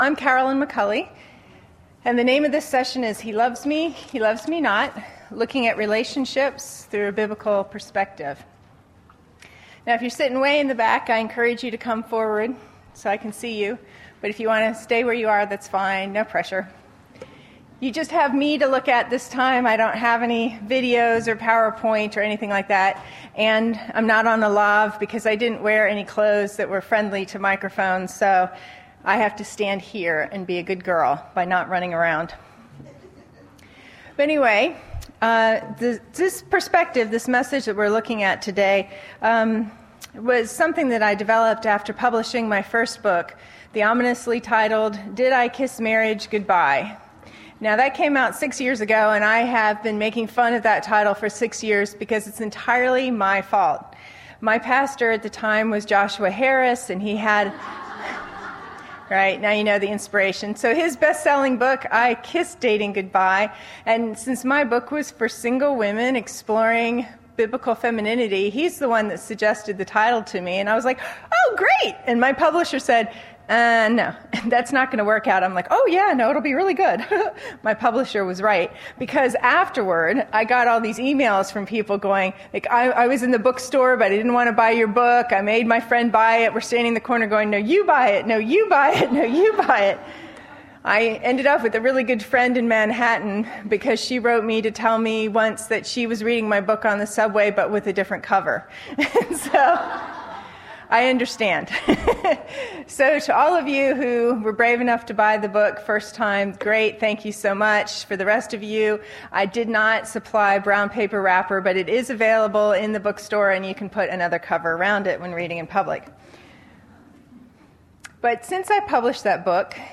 He Loves Me/He Loves Me Not: Looking at Love from the Bible's Perspective | True Woman '10 Indianapolis | Events | Revive Our Hearts
This breakout session addresses the challenges and opportunities of romance, friendship, and wise living for the single woman.